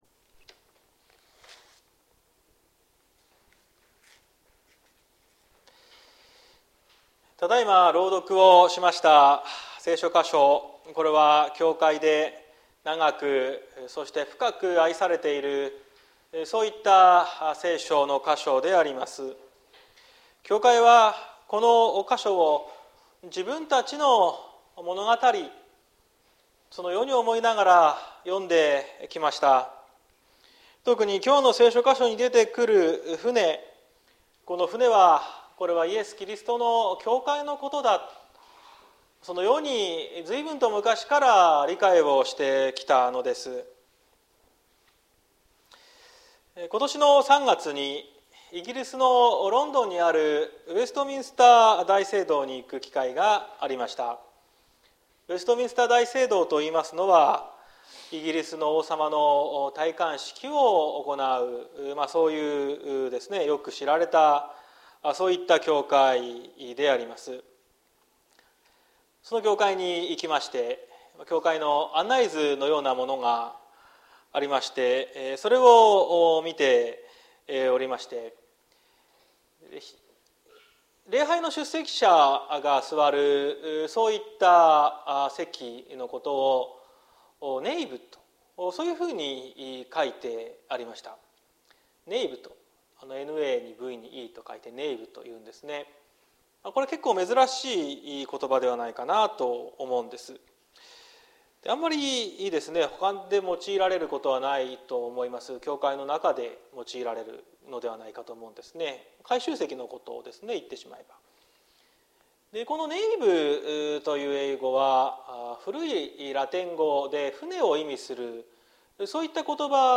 2023年07月09日朝の礼拝「嵐から平安へ」綱島教会
説教アーカイブ。